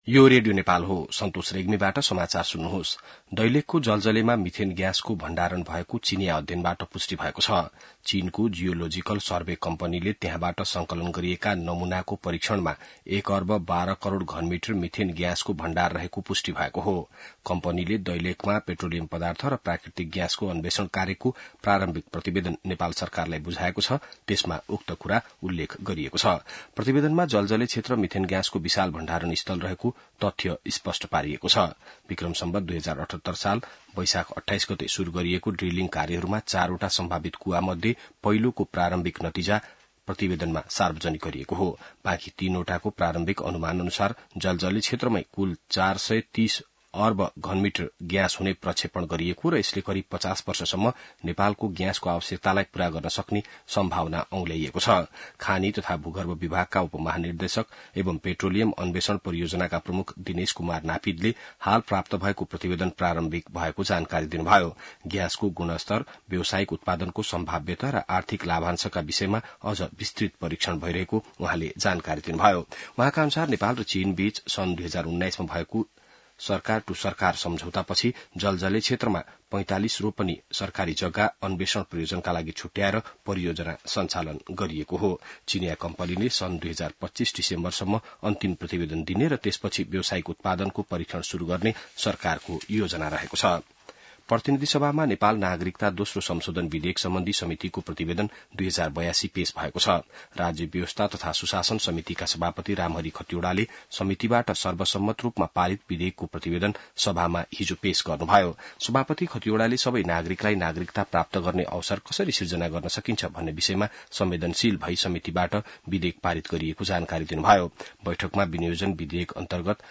बिहान ६ बजेको नेपाली समाचार : ६ असार , २०८२